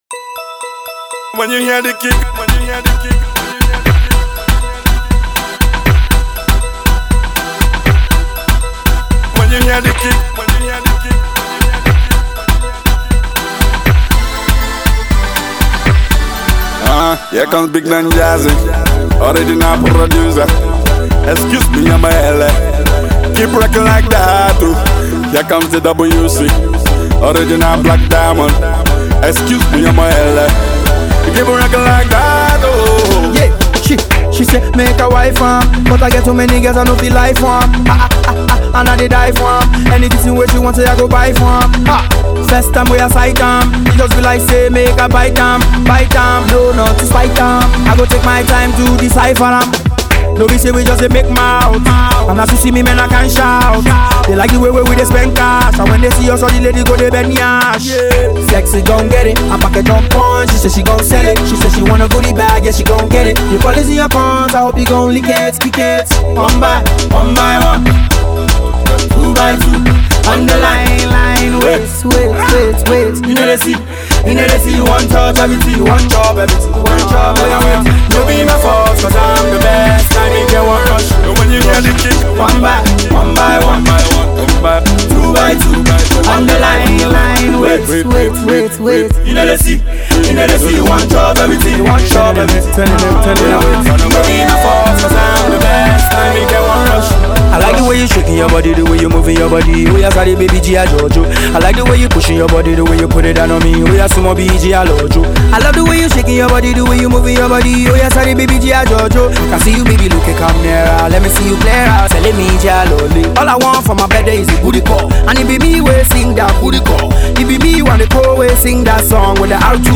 It’s got a fresh beat and is sure to get you dancing.